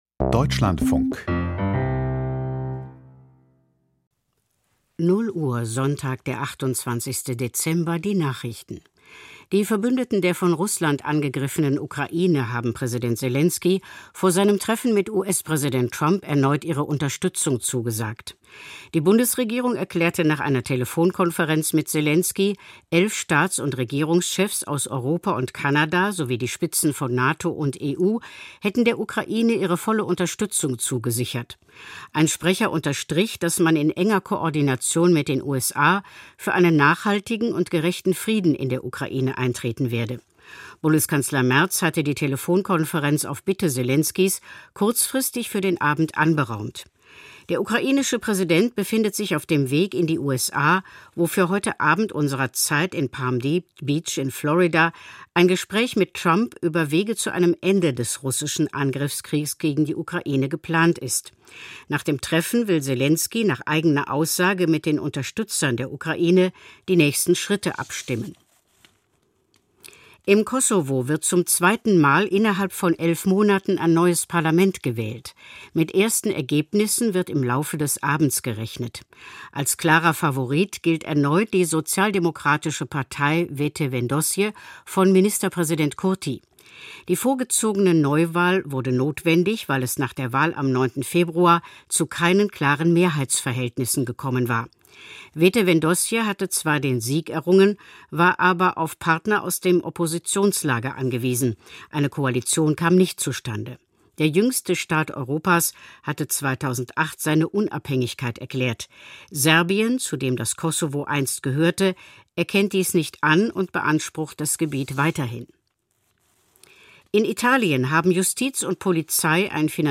Die Nachrichten vom 28.12.2025, 00:00 Uhr